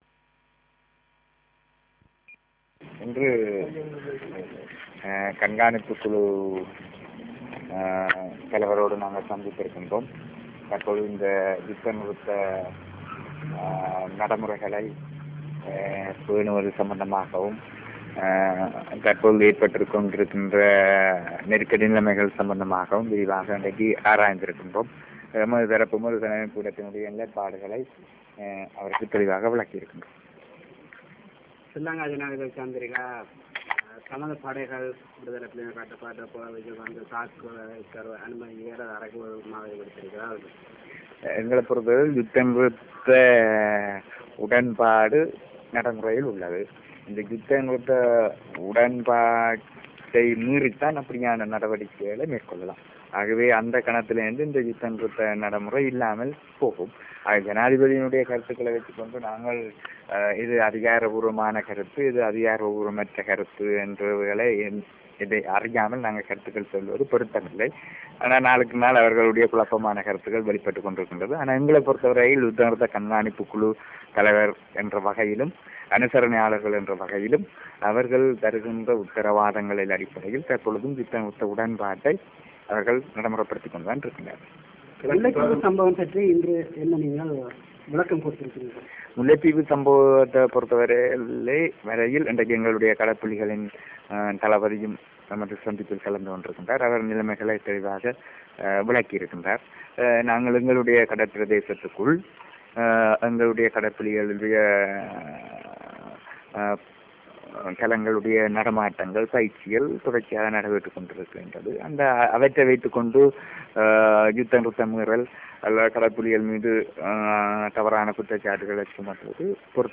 Real Audio Icon Press briefing by Mr. S.P. Thamilchelvan (Tamil)